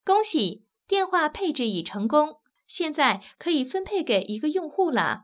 ivr-phone_is_configured_properly.wav